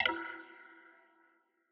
Godzilla Perc 1.wav